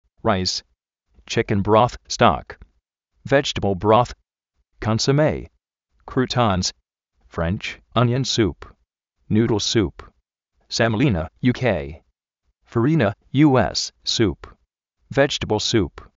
ráis
chíken broz (stók)
védch-tabl bróz
(frénch) ónion súp
nú:dl súp